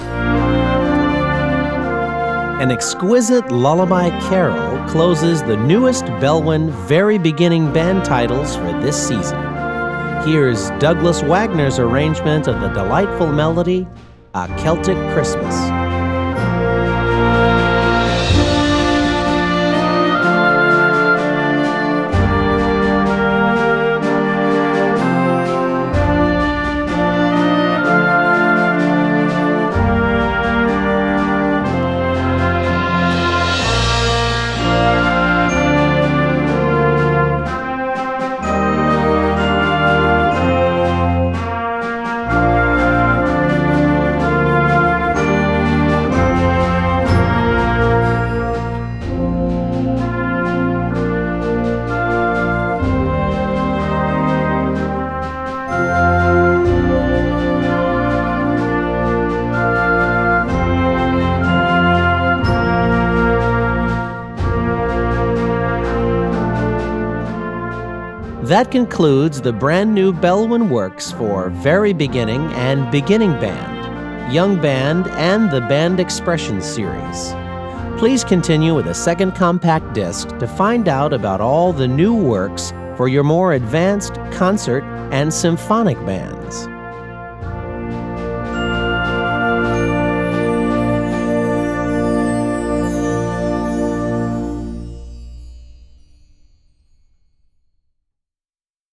Komponist: Volksweise
Besetzung: Blasorchester